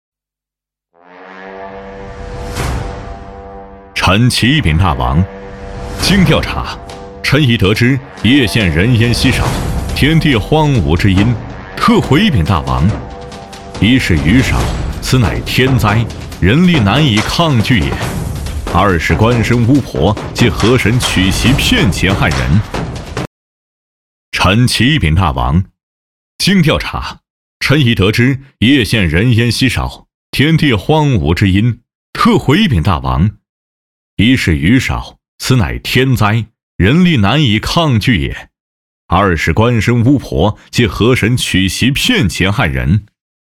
男29号
西门豹（角色）